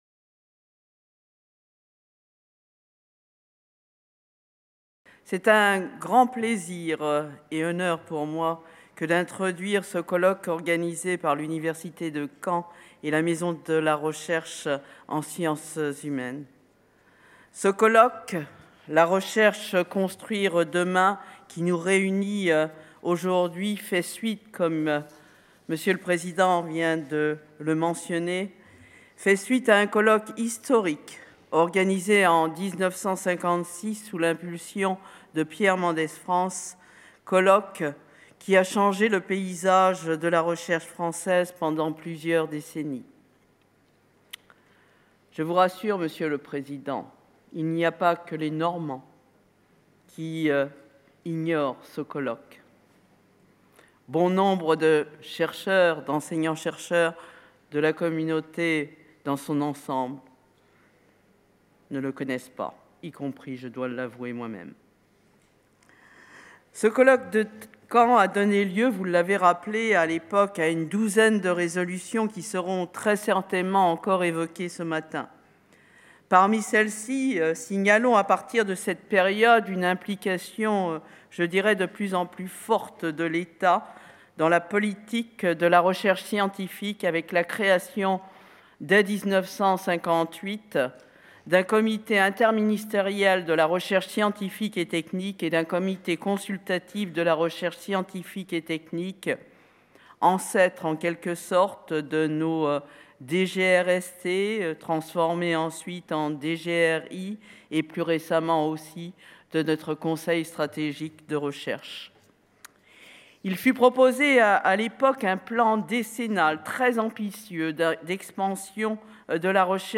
La Recherche. Construire demain. Discours introductif (Françoise BARRÉ-SINOUSSI) | Canal U
C’est le sens de la journée nationale qui s'est tenue à Caen le 3 novembre 2016 - journée qui s'est conclue par une allocution du président de la République.